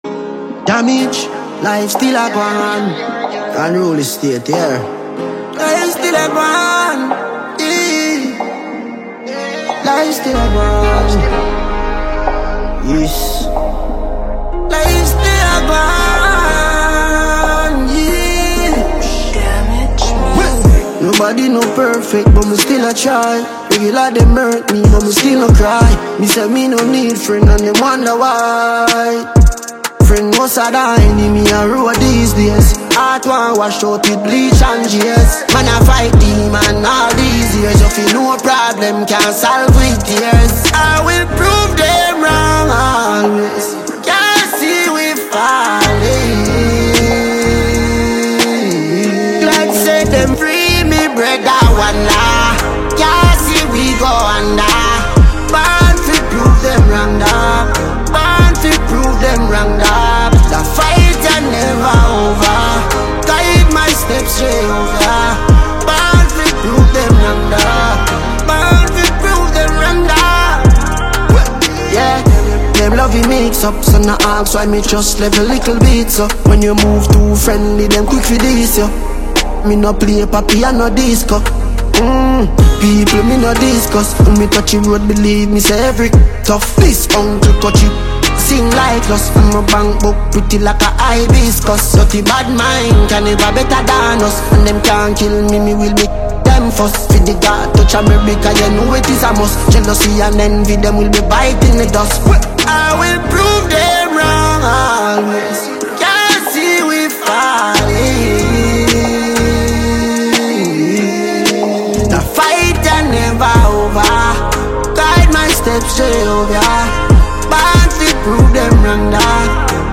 DancehallMusic